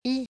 “i” as in “chic”
“i” sound is always pronounced as in “chic” or “petite”.
So in order to pronounce the “i” sound, you will do a shorter version of the English “ee” sound.